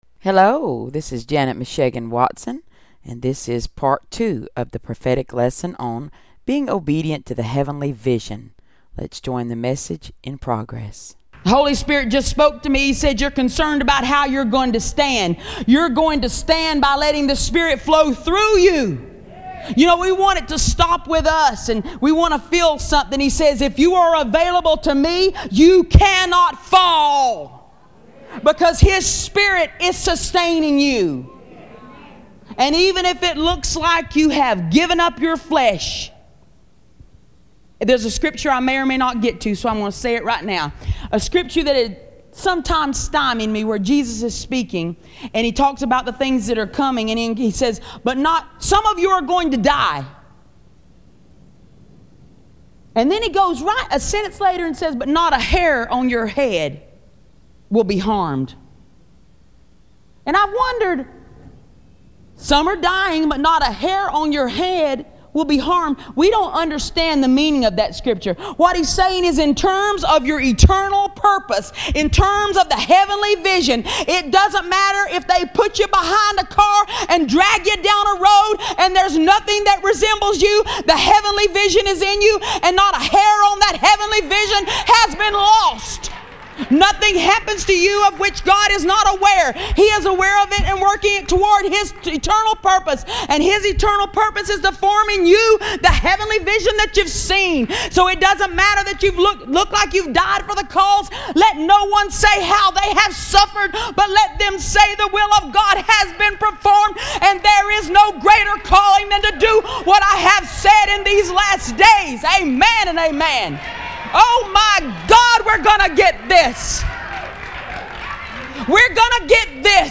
Prophetic_Lesson_on_Being_Obedient_to_the_Heavenly_Vision_Part_2.mp3